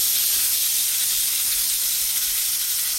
Preview and download these AI-generated food & drink sounds.
Sizzling Pan
Food sizzling in a hot pan with oil popping and steam rising
sizzling-pan.mp3